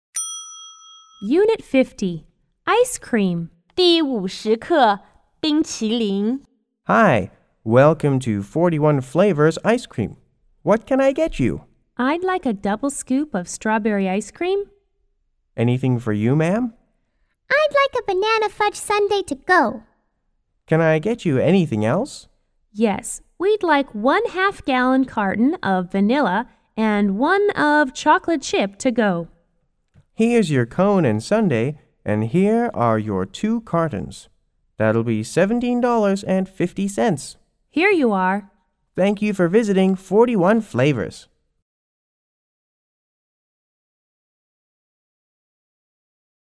C= Counter Person C= Customer 1 C= Customer 2